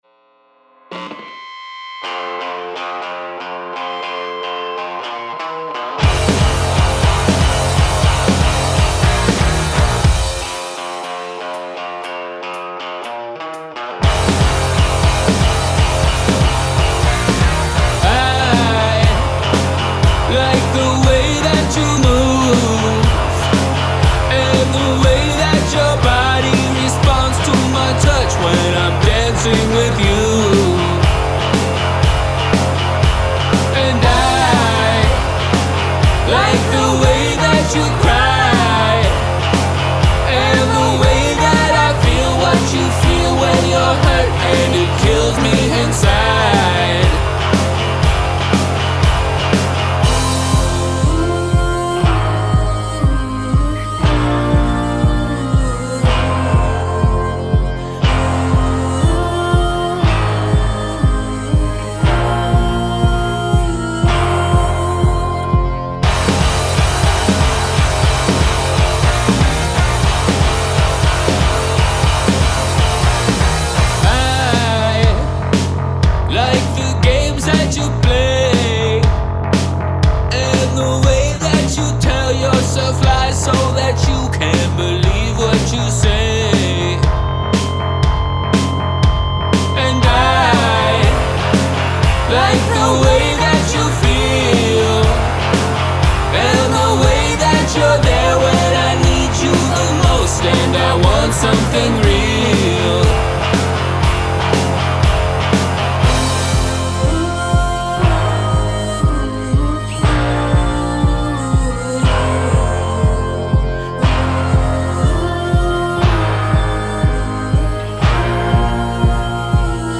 The feedback in the guitars and the buzzing noises are intentional and some of the timing is a bit sloppy and the hits on the snare are not entirely consistent, but it's rock 'n roll.
For the most part yes, I am recording at home.
Mostly stringed instruments like slide guitar, dobro, banjo, etc. There were a few other parts that people played, but I recorded those at my place as they were passing through.
Mostly a 57 and a Studio Projects B1 condenser.
I EQ'd the kick and snare a touch so they were less murky and muddled (and took the verb down on them a dB). I took a bit of harshness out of the intro guitar and I took the background vocals down a touch as well. Lastly I took some of the master bus compression down and instead sent it to another bus where I essentially used parallel compression and brought that up until I could just barely hear it and then backed it down.